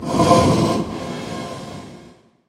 sounds / mob / blaze / breathe3.mp3
breathe3.mp3